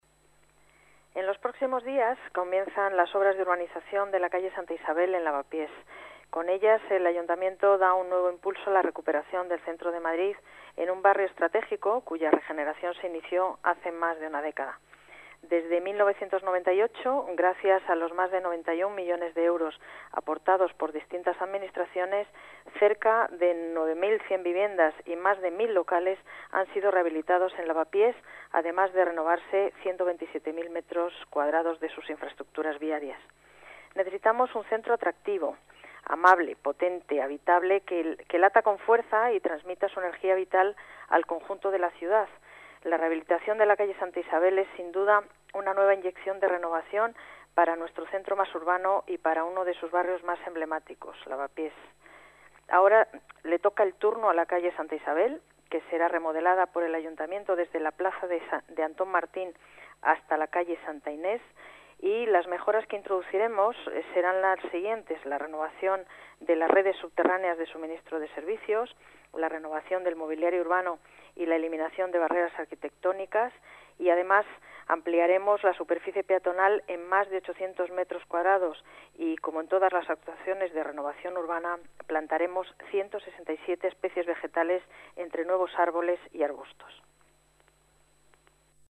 Nueva ventana:Declaraciones de Paz González, delegada de Urbanismno y Vivienda